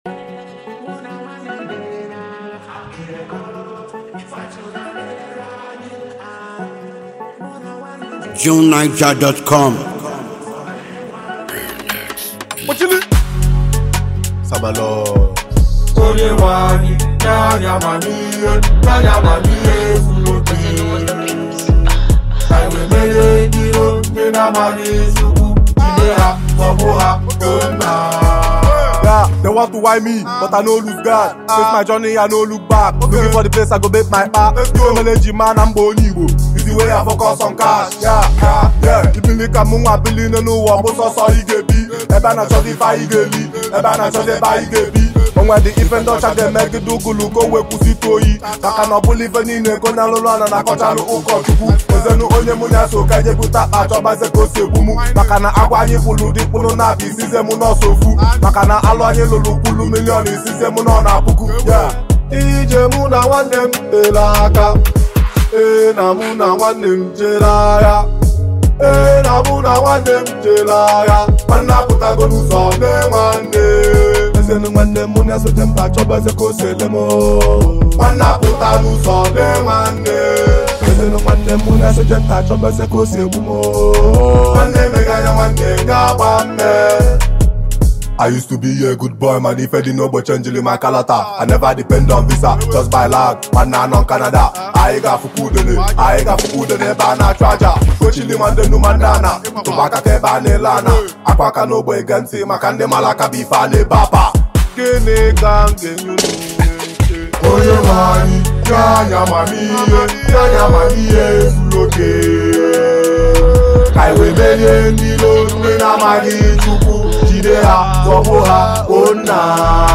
indigenous rap